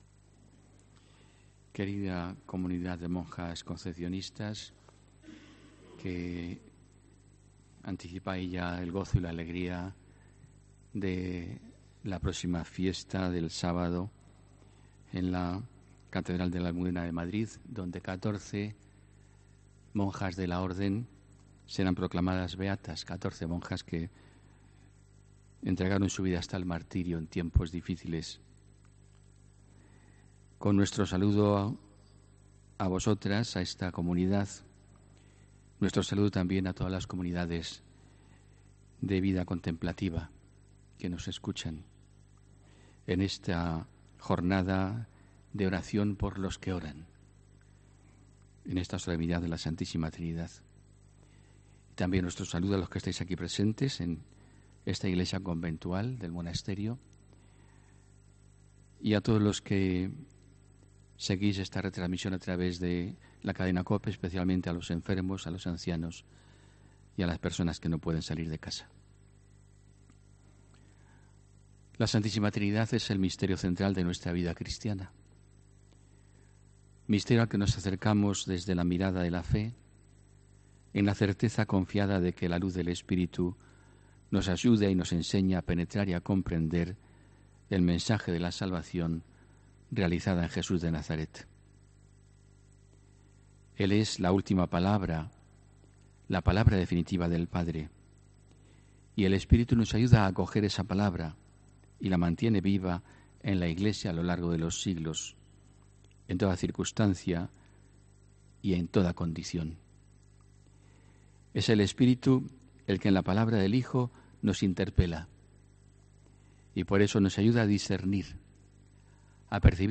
HOMILÍA 16 JUNIO 2019